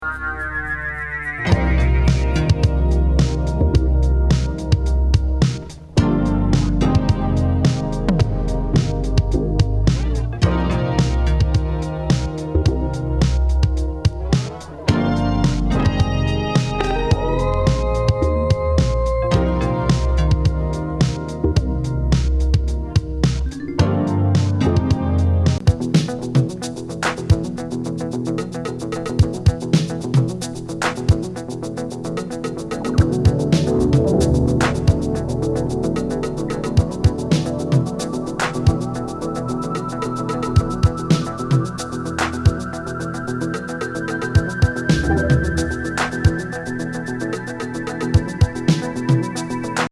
スムース・エレクトロニクス・グルーブ